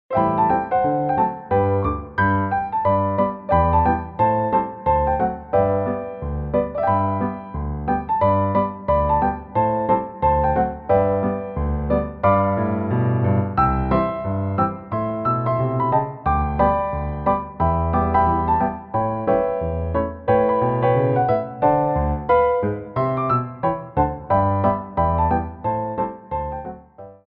33 Tracks for Ballet Class.
Glissés
4/4 (16x8)